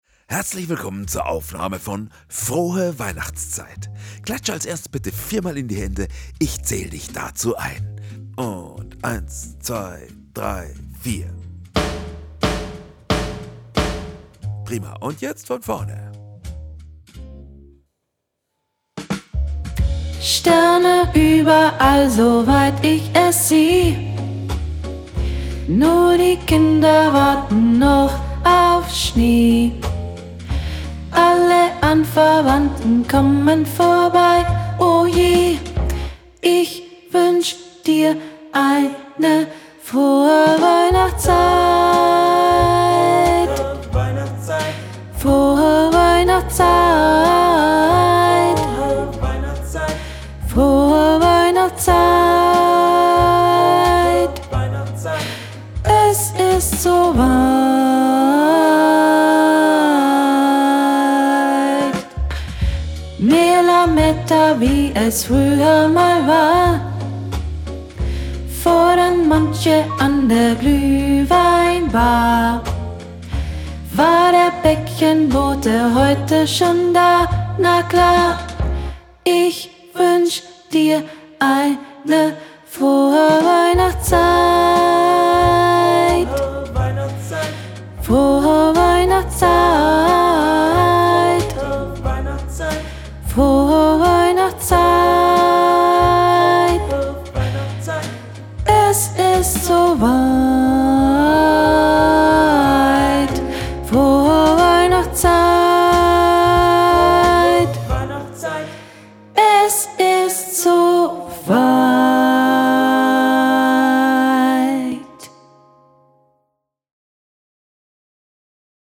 Playback für Video - Mezzo
Frohe_Weihnachtszeit - Playback fuer Video - Mezzo.mp3